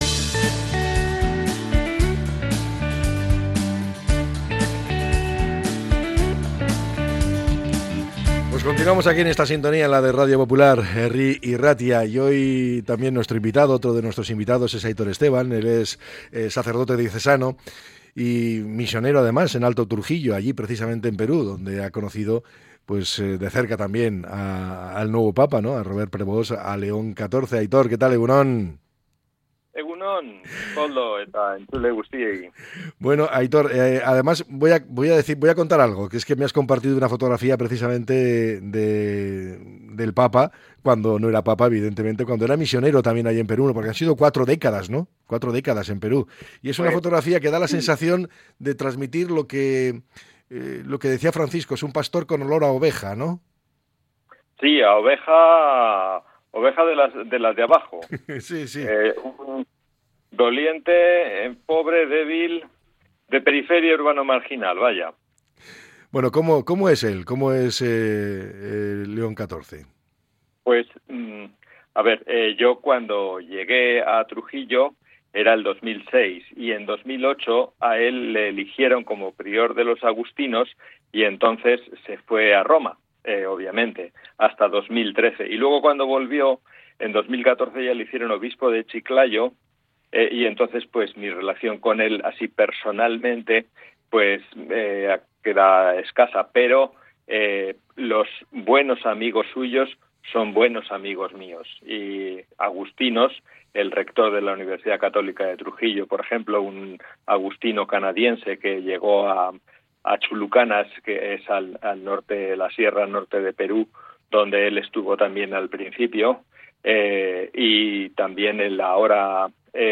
Durante la entrevista, se ha destacado también la elección del nombre papal.